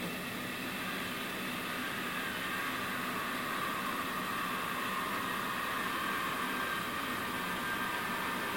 描述：我上传的关闭声音效果的第三个版本。
标签： 抽象 怪异
声道立体声